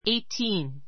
eitíːn
（ ⦣ gh は発音しない）